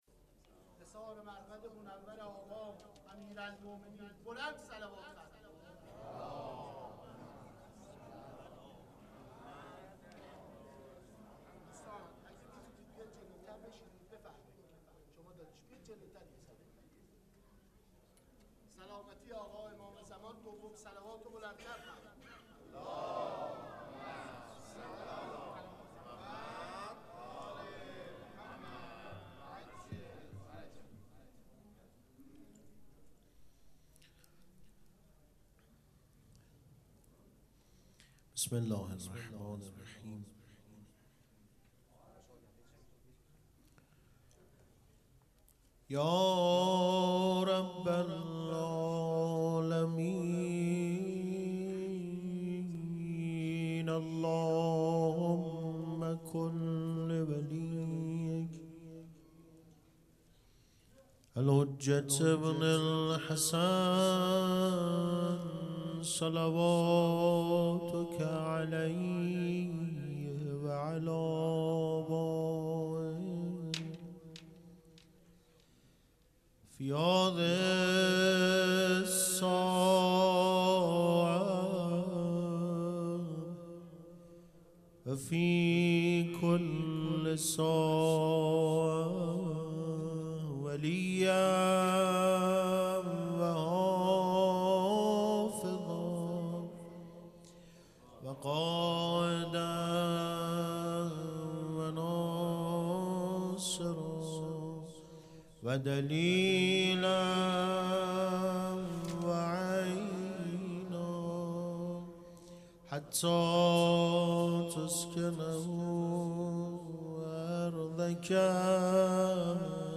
پیش منبر